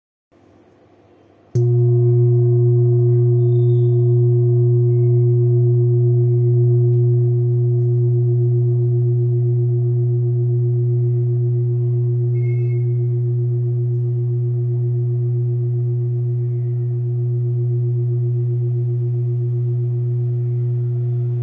Old Hand Beaten Bronze Kopre Singing Bowl with Antique
Material Bronze
It is accessible both in high tone and low tone .
In any case, it is likewise famous for enduring sounds.